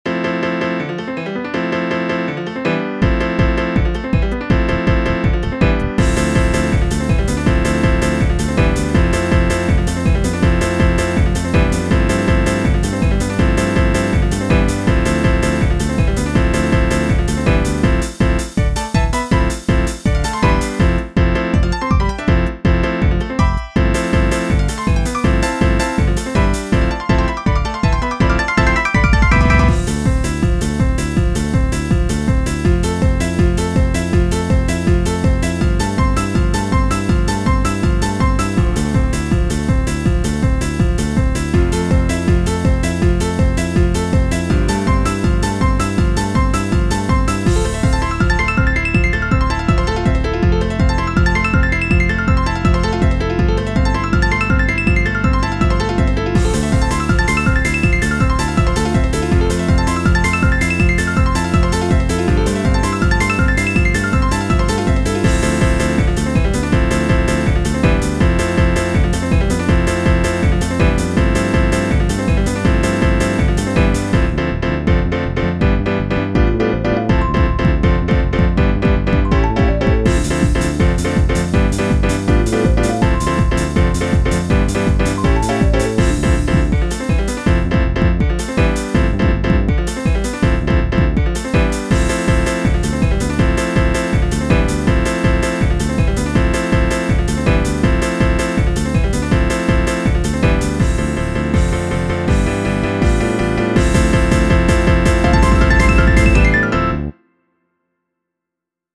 BPM162
Audio QualityPerfect (High Quality)
A happy sounding piano driven song.